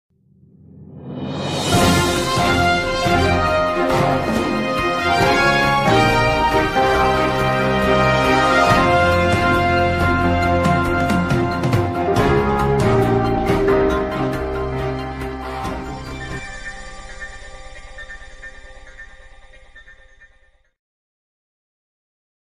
Breaking News Sound Effect